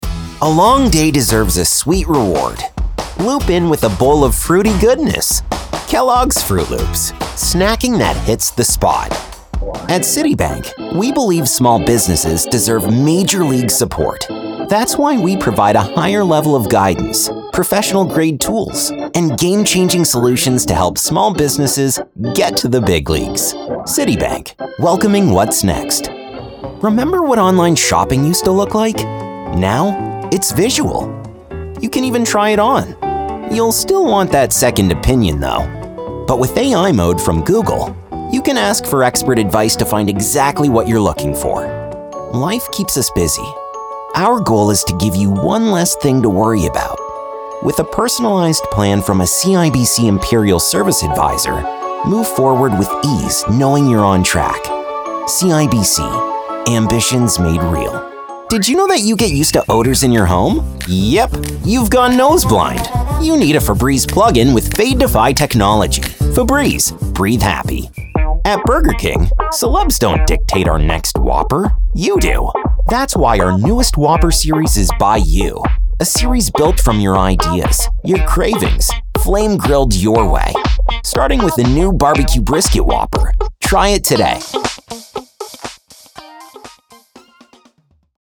The Voice Realm represents versatile American and Canadian voice over talent with North American accents suited to international voice castings from small jobs to worldwide campaigns.